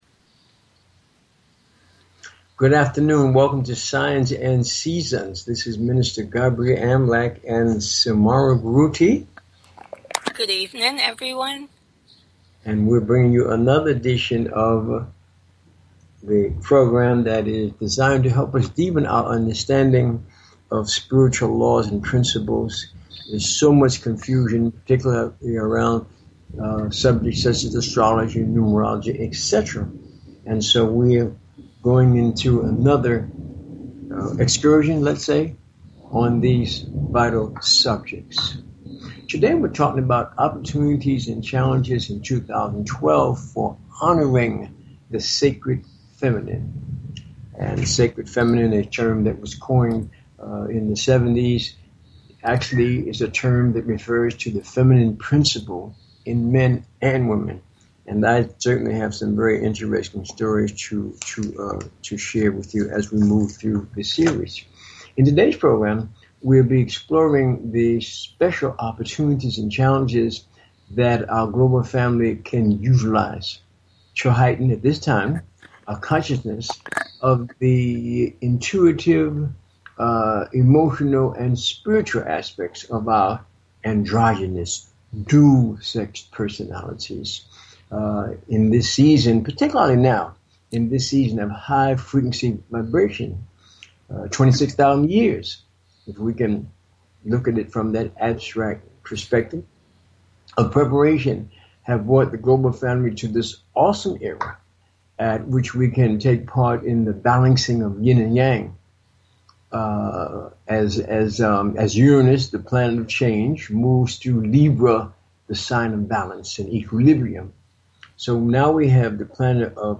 Talk Show Episode, Audio Podcast, Signs_and_Seasons and Courtesy of BBS Radio on , show guests , about , categorized as
The Primary objectives of this broadcast are: To ELIMINATE the fearful and superstitious attitudes that many peple have towards Astrology and related "Metaphysical" teachings by providing information on the constructive uses of these ancient sciences. And to demonstrate the practical value of Astrology and Numerology by giving on air callers FREE mini readings of their personal Astro-numerica energy profiles.